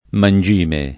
mangime [ man J& me ] s. m.